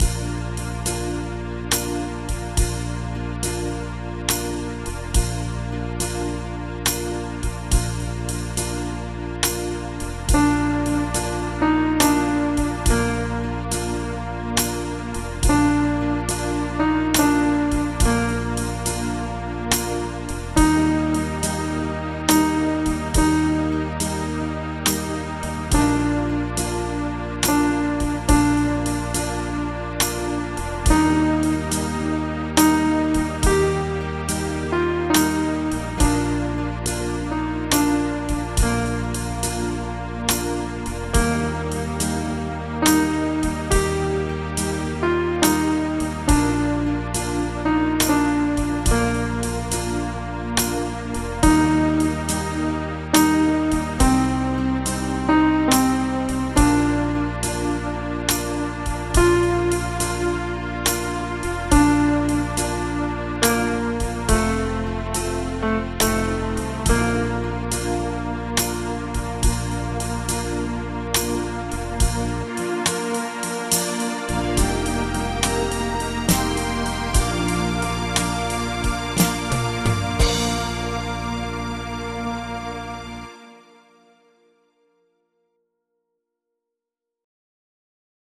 Audio Midi Bè Alto: download